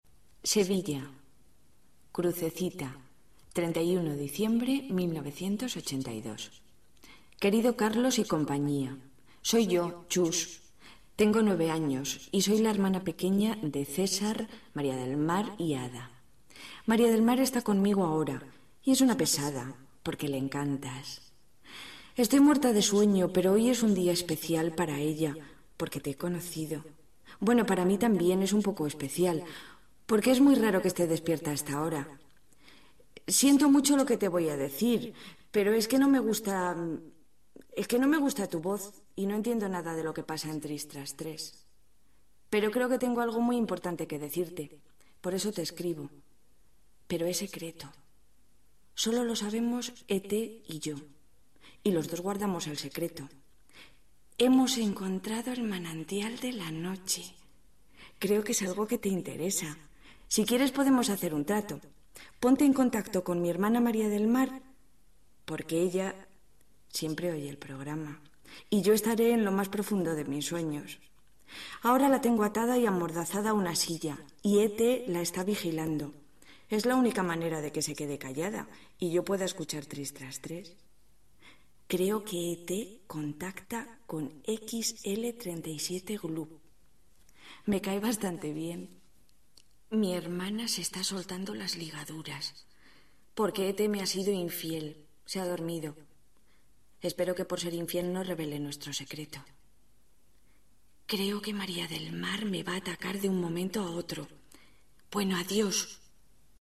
Lectura de la carta escrita per una suposada oïdora infantil, amiga d'ET, per a la secció 'Cartas de oyentes'
Entreteniment
FM